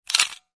camera.ogg